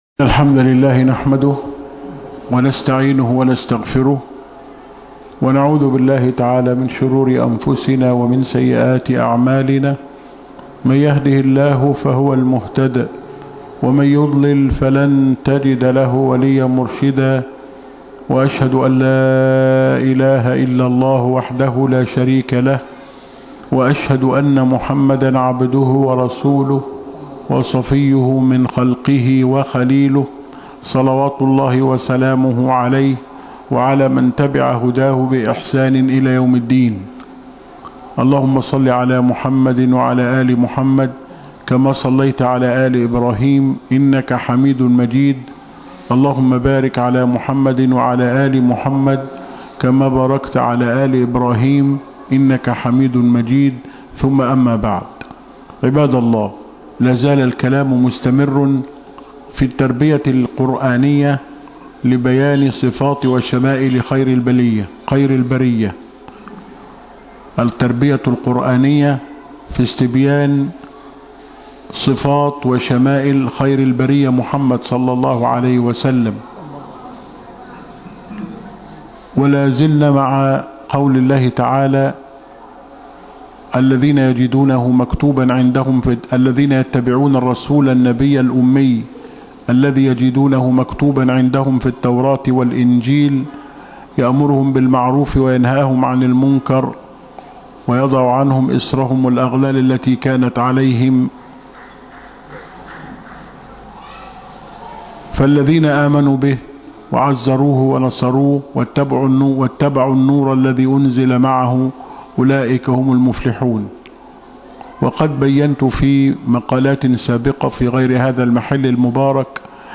خطب الجمعة